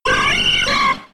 Cri de Persian K.O. dans Pokémon X et Y.